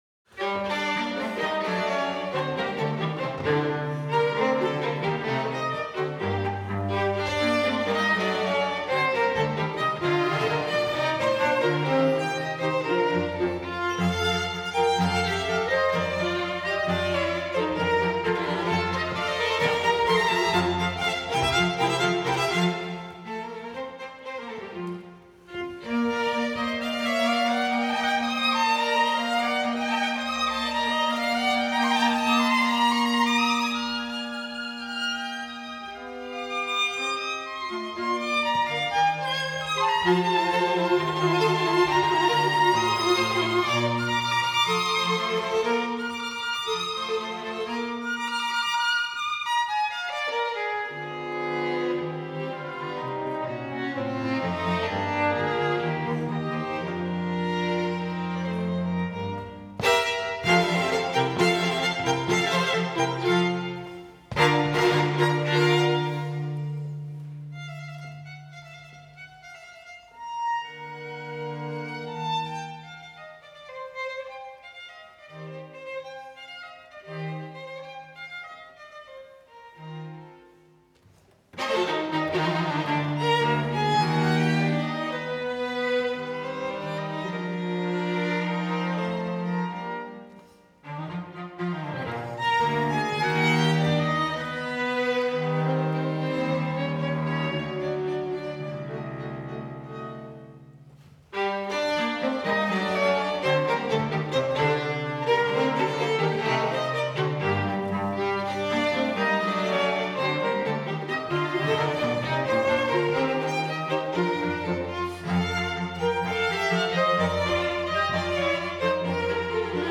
Venue: St. Brendan’s Church
Instrumentation Category:String Quartet